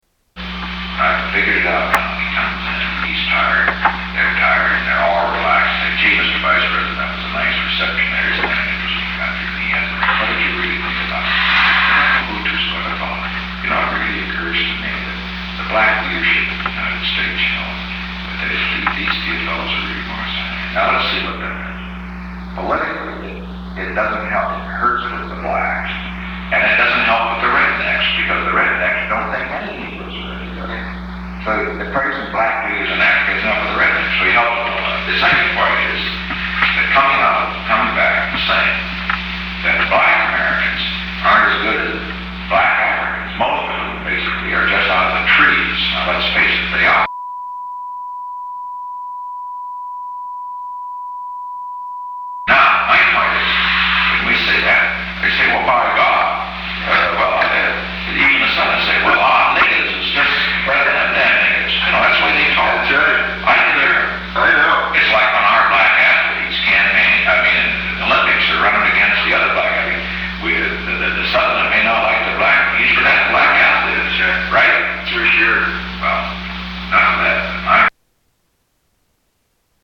Tags: White House tapes Presidents Secret recordings Nixon tapes White house